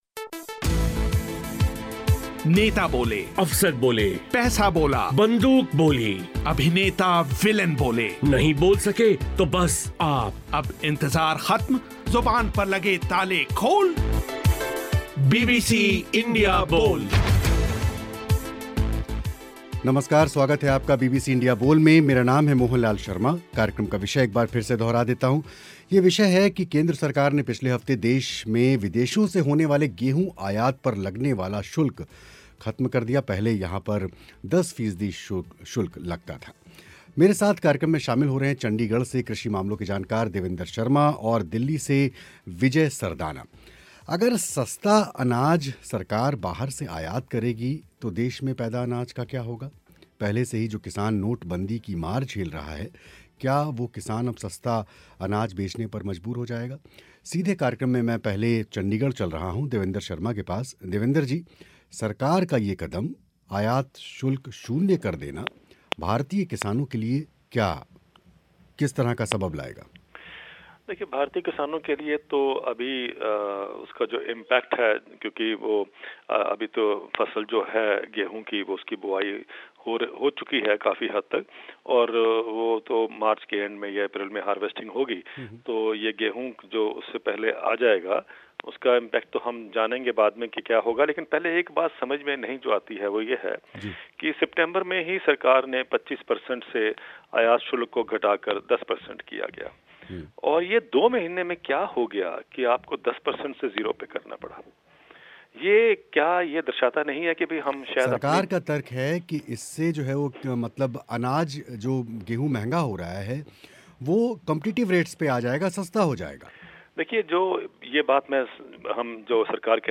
गेहूँ से आयात शुल्क हटाकर क्या किसानों की मदद कर रही है नरेंद्र मोदी सरकार? विदेशों से गेहूँ ख़रीदा गया तो अपनी फ़सल का क्या करेगा भारतीय किसान? कार्यक्रम में इसी विषय पर हुई चर्चा